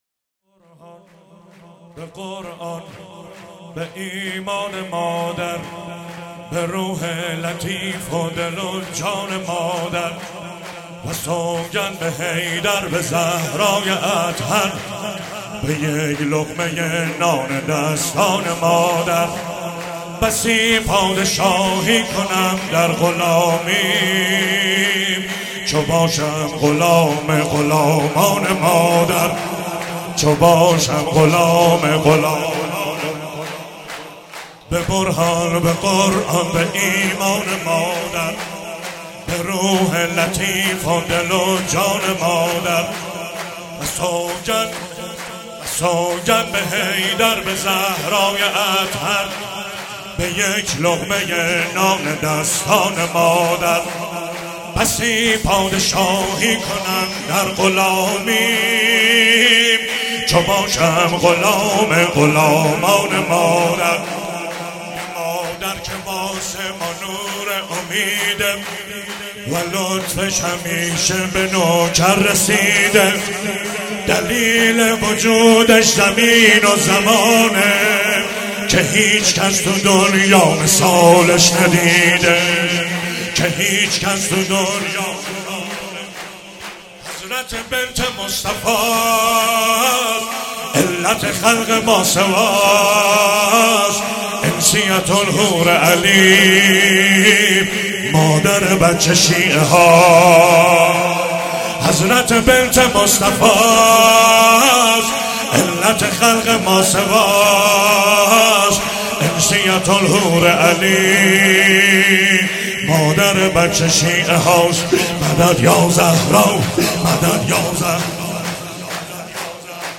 مراسم هفتگی 26 اسفند 95
چهاراه شهید شیرودی حسینیه حضرت زینب (سلام الله علیها)
شور